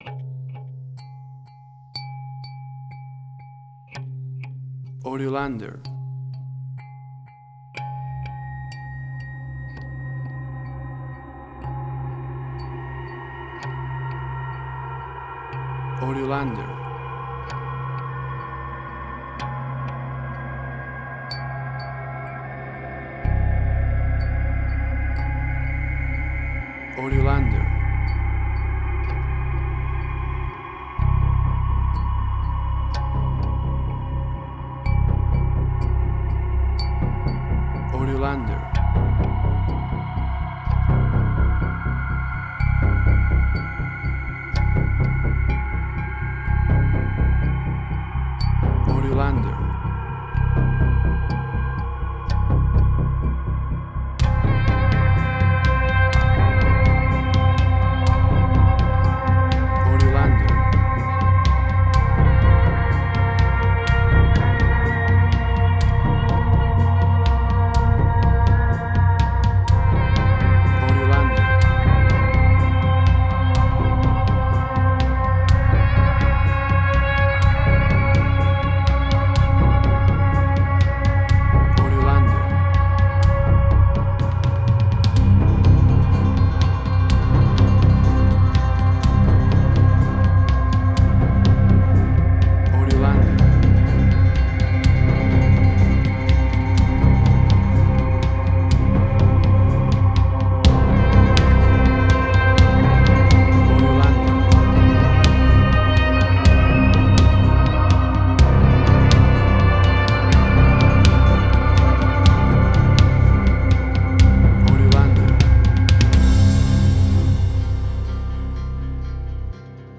Post-Electronic.
Tempo (BPM): 62